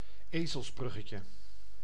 Ääntäminen
US : IPA : /nəˈmɑːnɪk/